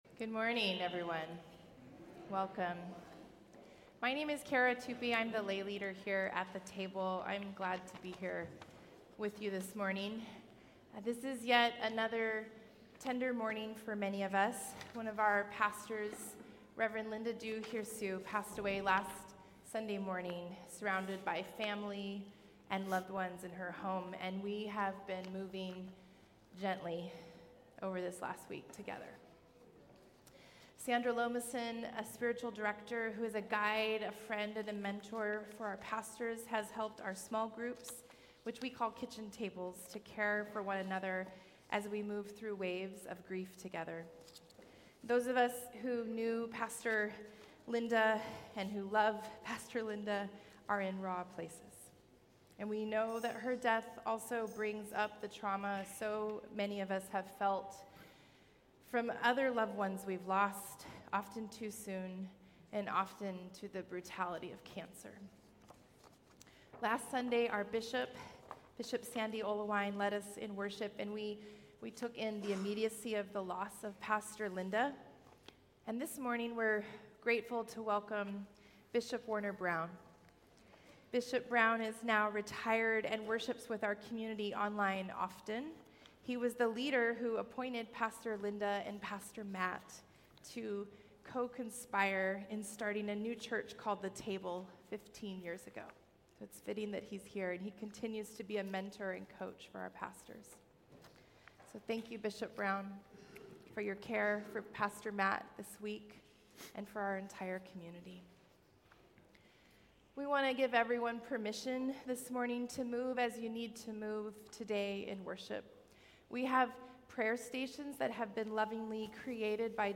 Compelled by a Wild God. February 23, 2025 Bishop Warner Brown growing in faith , reaching in love , rooted in grace Edge Walking Luke Watch Listen Save We welcome guest Bishop Warner Brown for the message today.